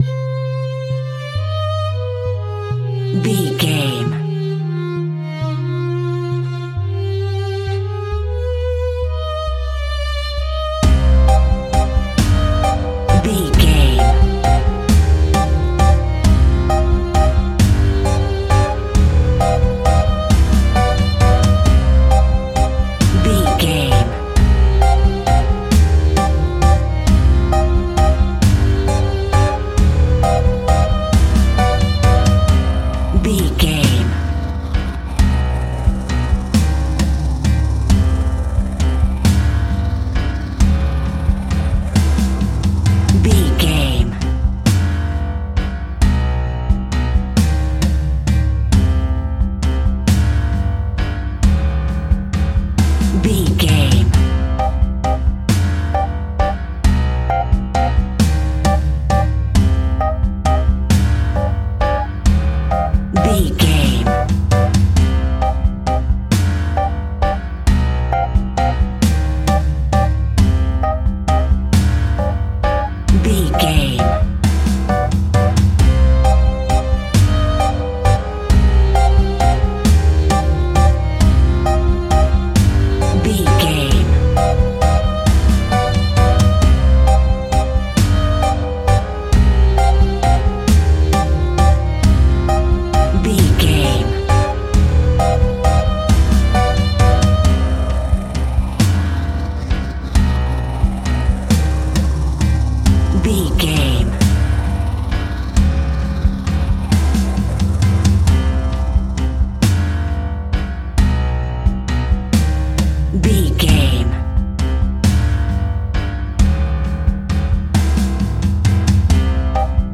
Haunting Vampire Music.
Aeolian/Minor
E♭
tension
ominous
dark
eerie
piano
synthesizer
horror